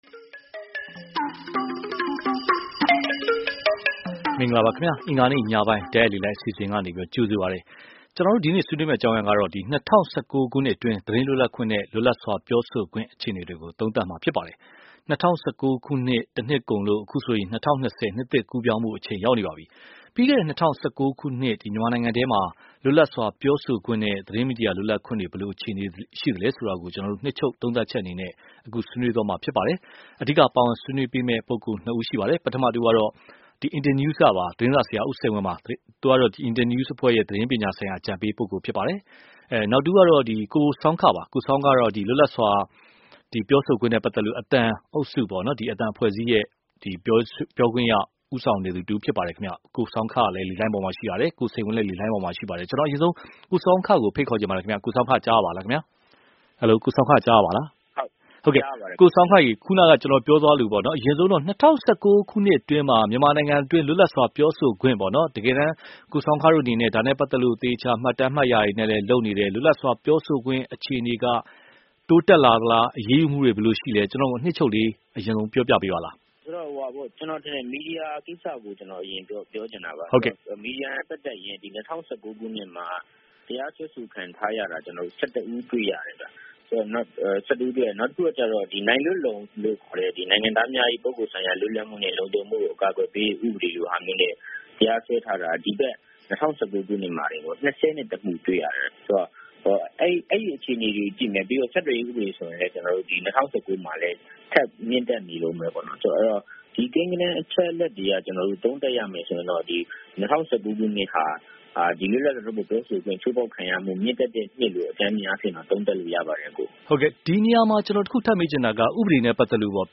၂၀၁၉ သတင်းလွတ်လပ်ခွင့်နဲ့ လွတ်လပ်စွာပြောဆိုခွင့် (တိုက်ရိုက်လေလှိုင်း)